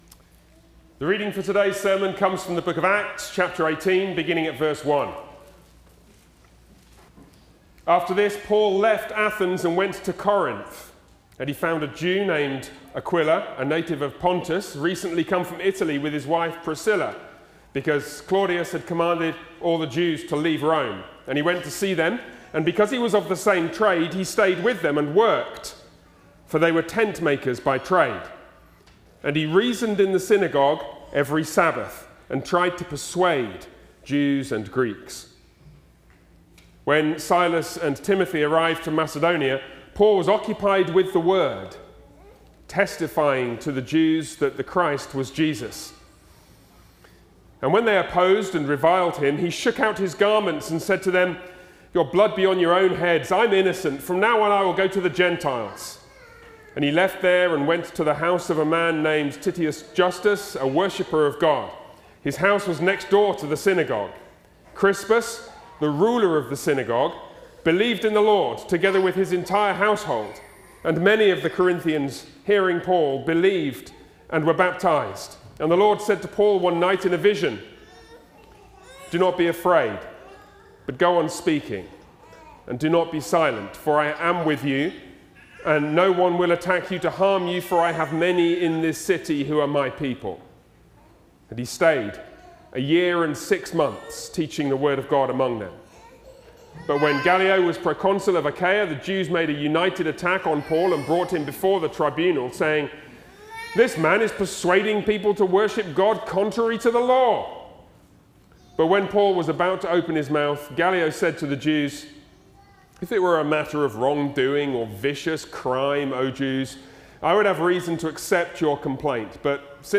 Sermons on Acts Passage: Acts 18:1-17 Service Type: Sunday worship Download Files Bulletin Topics